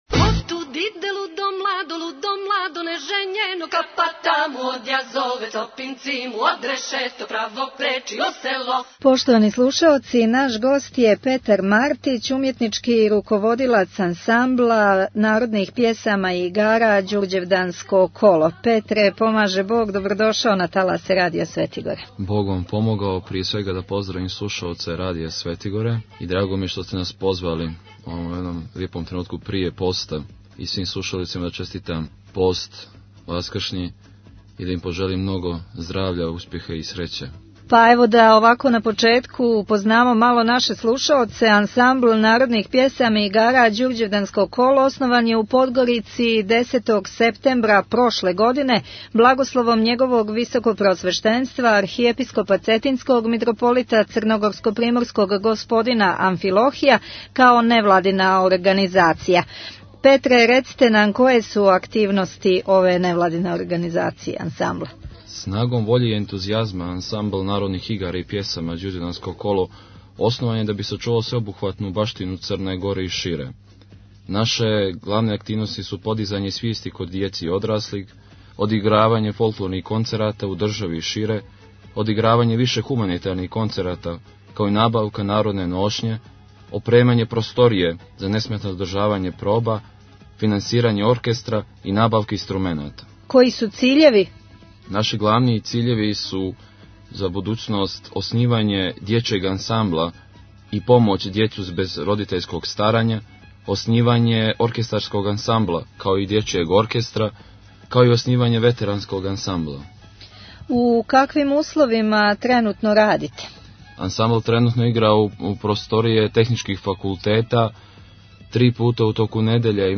"Ђурђевданско коло" Tagged: Актуелни разговори 5:38 минута (994.58 КБ) Благословом Његовог Високопреосвештенства Архиепископа Цетињског Митрополита Црногорско - приморског Г. Амфилохија 10. септембра 2008. године у Подгорици је основан ансамбл народних пјесама и игара "Ђурђевданско коло".